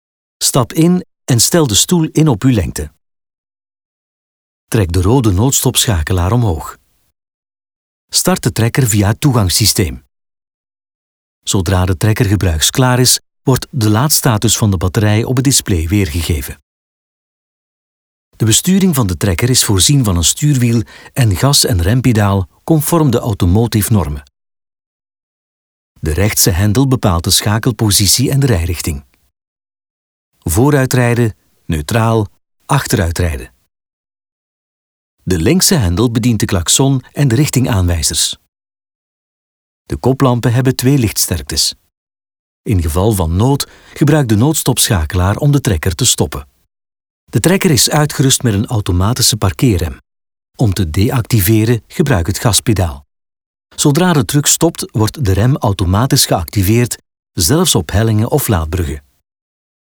Natural, Reliable, Commercial, Versatile, Warm
E-learning